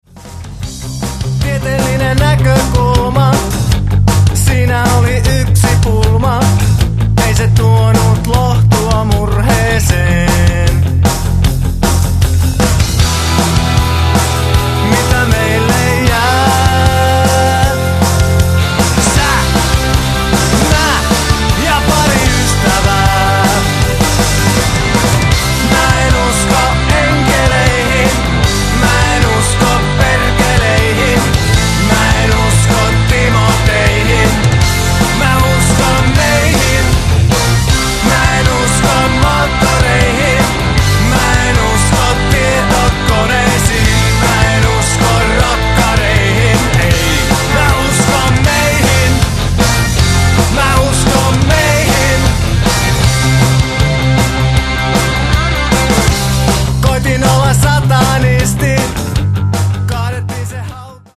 (Compressed/Mono 479kb)